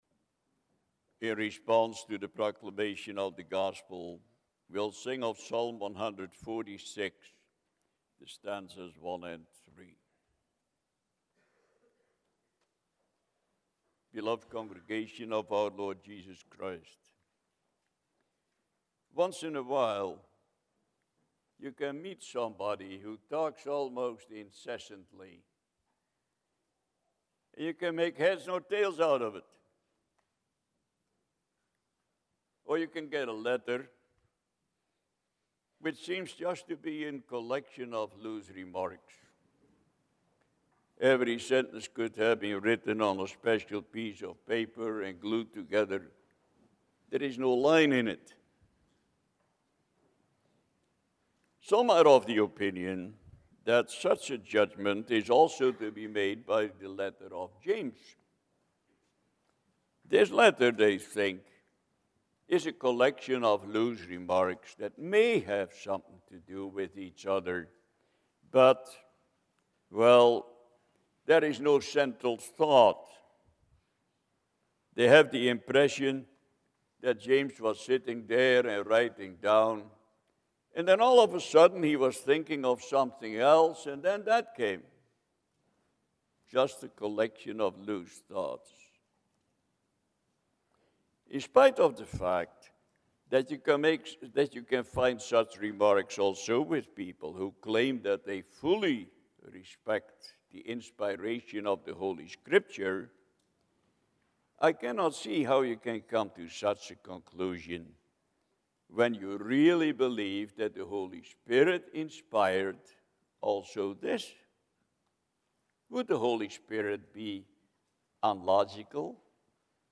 James 1:1-18 Service Type: Sunday Morning Bible Text